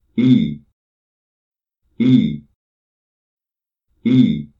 eee (long sound)